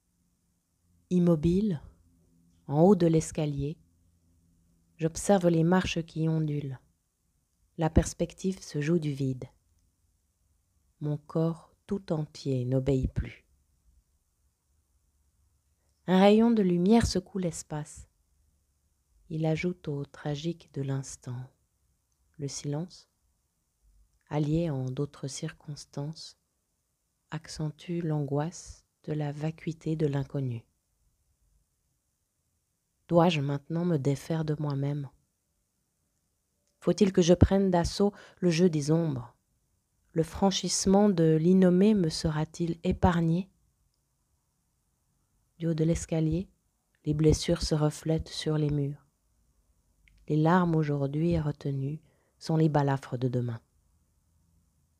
Écouter un extrait lu par